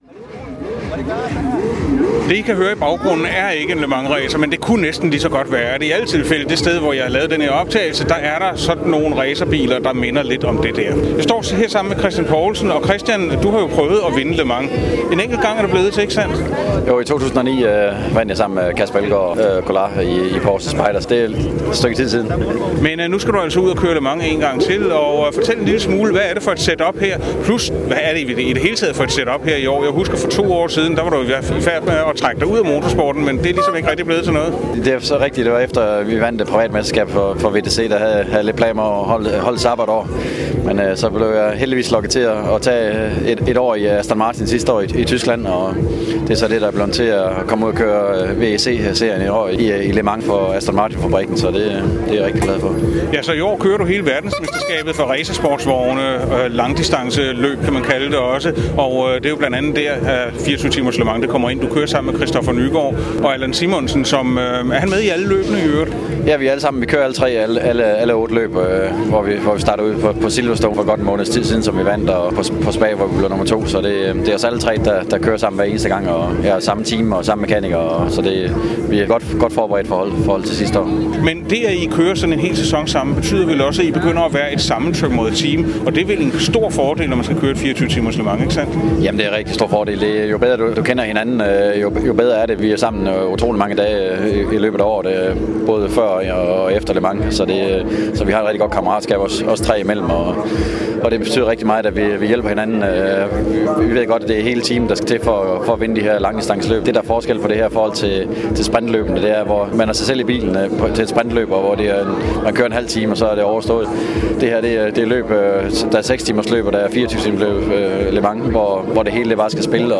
Interview i Motorradioen